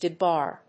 音節de・bar 発音記号・読み方
/dɪbάɚ(米国英語), dɪbάː(英国英語)/